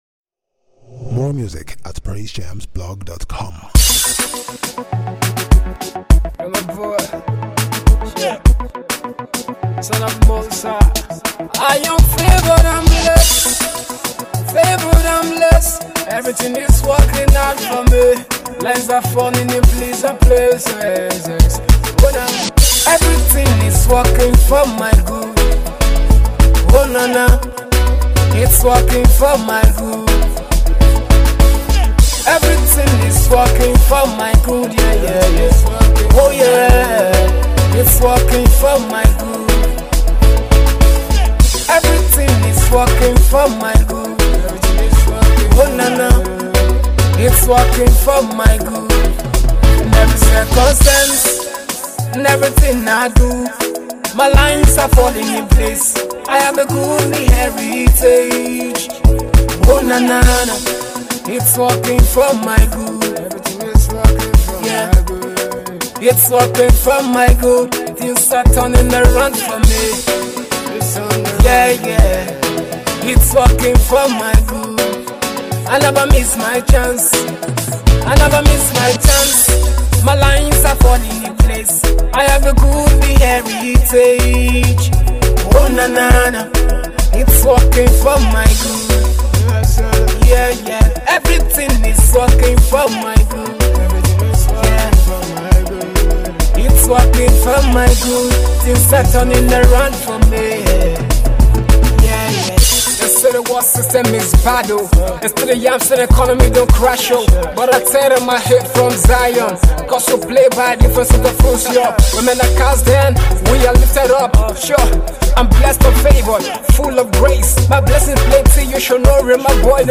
prophetic song
what a lovely title for a grooving song!